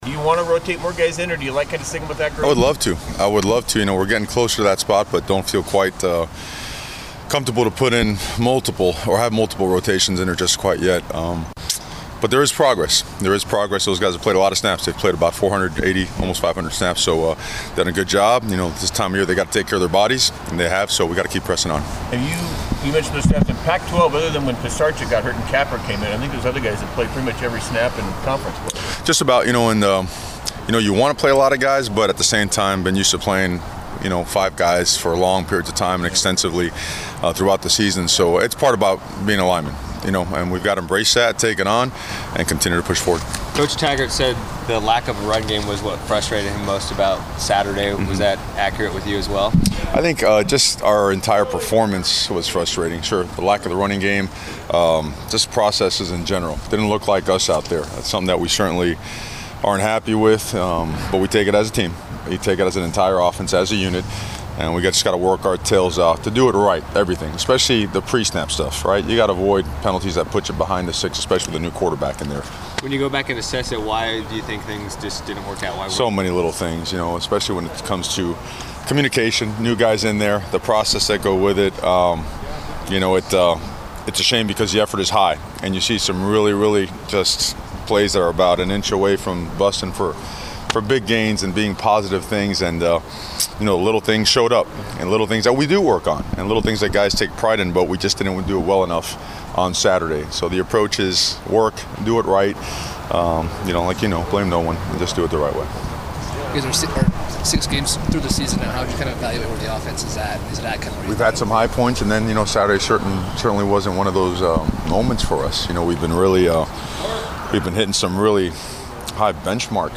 Mario Cristobal Media Session 10-10-17